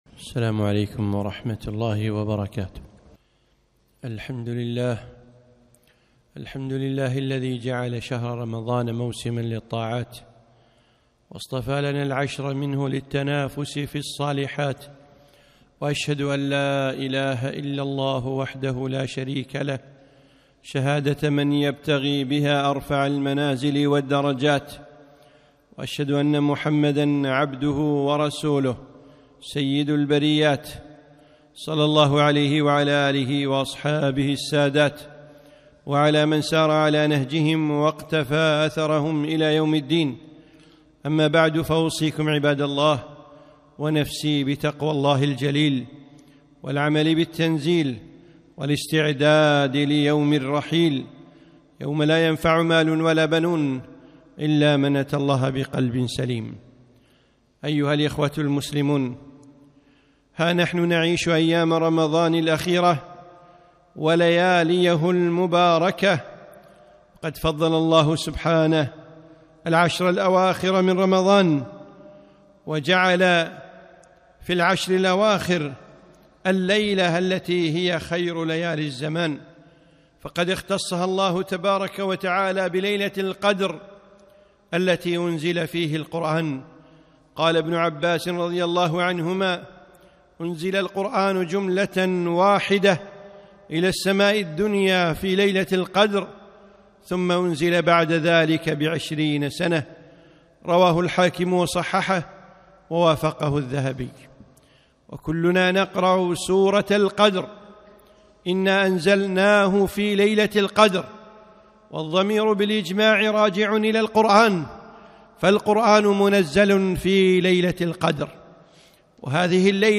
خطبة - العشر الأواخر